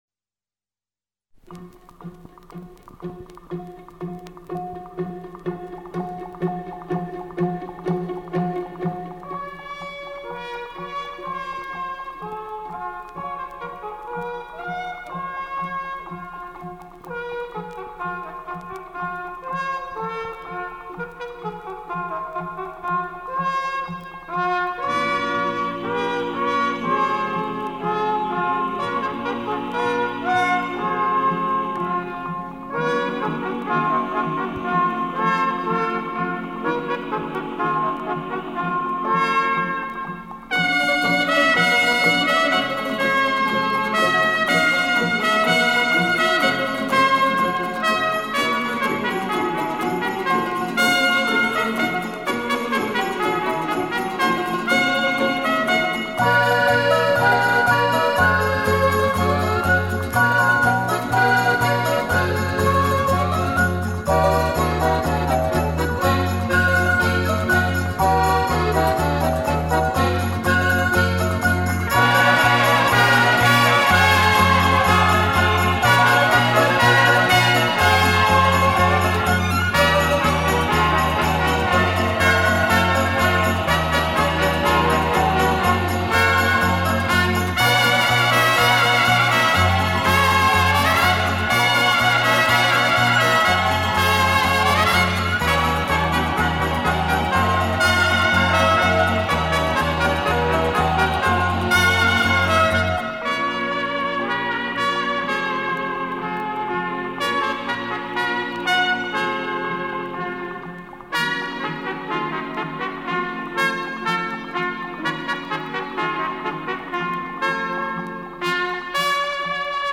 Как всегда моя любимая труба...